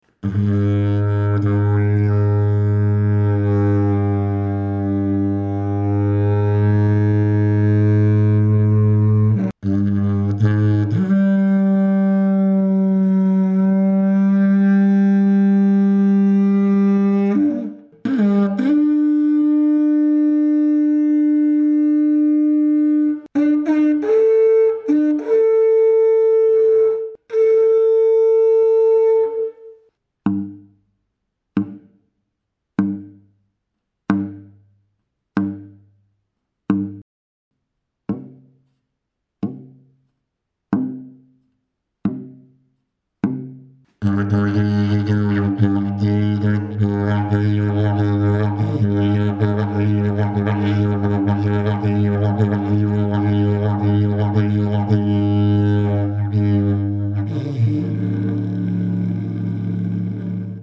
Dg469 ist ein kleines Didgeridoo des altbewährten Modells 004 (src), gestimmt in G2# Overblow etwas unter der Oktave G3.
Das Didgeridoo hat auf Grund seiner hohen Stimmlage einen scharfen und sehr schnell modulierbaren Sound.
Grundton, Ziehbereiche und Overblows: G2# (G2 bis G#+40) // G3-20 (-40, +20) / D+40 / A+20 The Dg469 is a small didgeridoo of the tried-and-tested 004 model (src), tuned to G2# with overblow slightly below the octave G3.
Fundamental note, draw ranges and overblows: G2# (G2 to G#+40) // G3-20 (-40, +20) / D+40 / A+20 Dg469 Technical sound sample 01